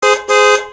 Autohupe klingelton kostenlos